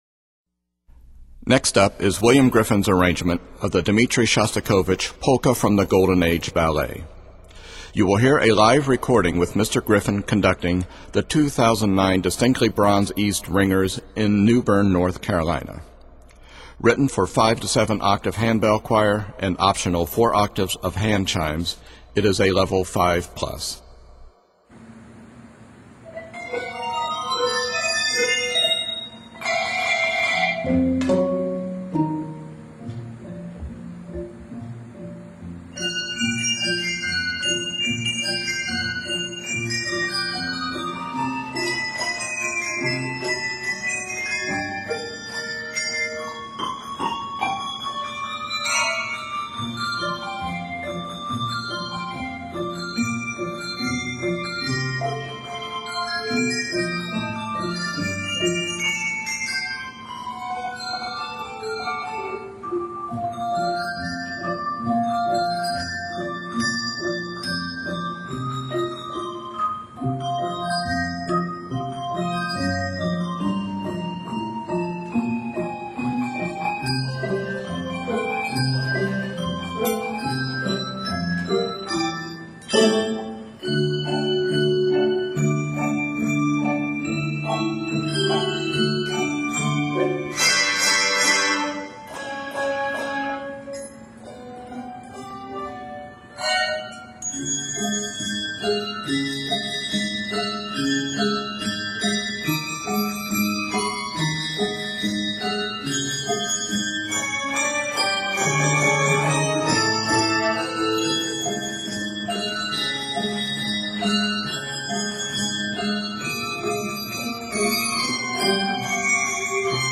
Fast-paced rhythms and accidentals abound.